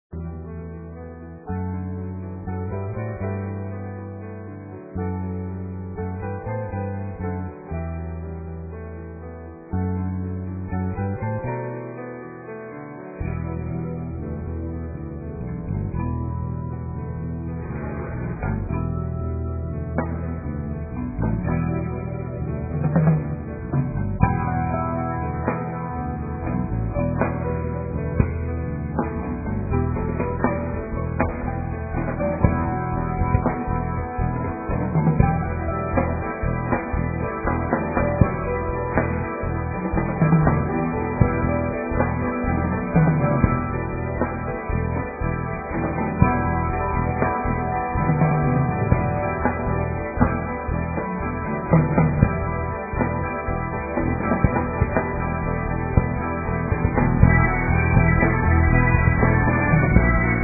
Uma sonoridade extremamente original.